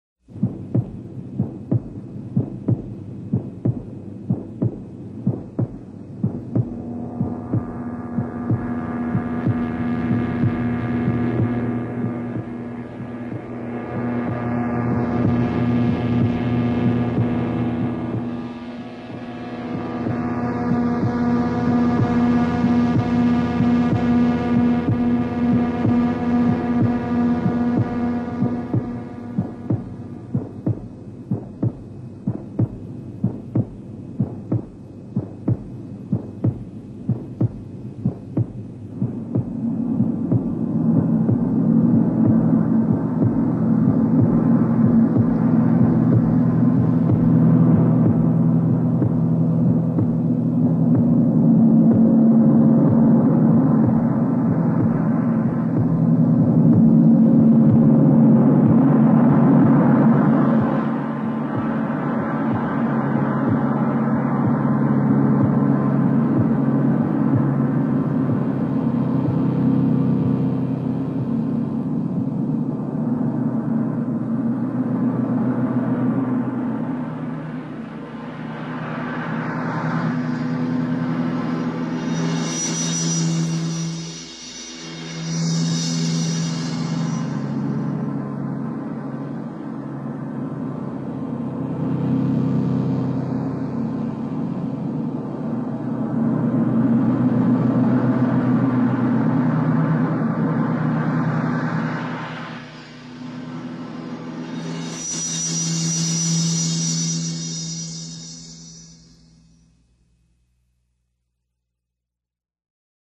Progressive Rock, Psychedelic Rock